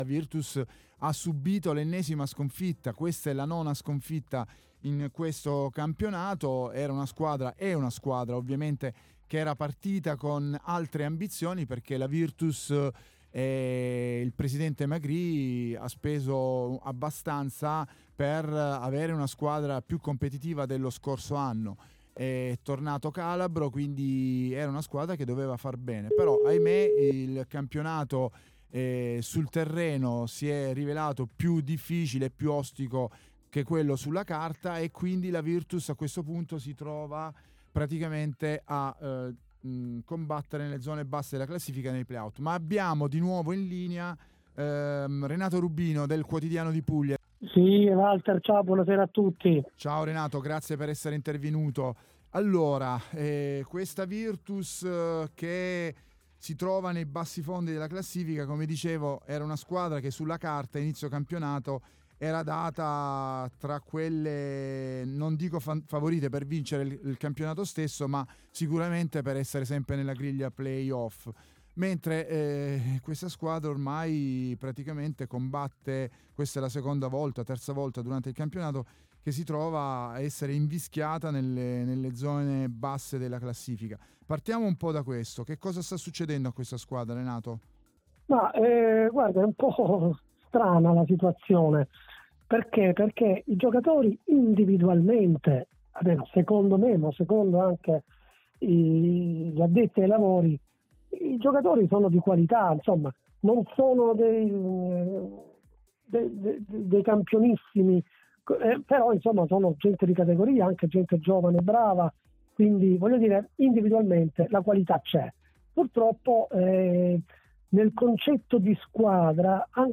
Diretta de "Il biancazzurro"